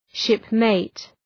Προφορά
{‘ʃıpmeıt}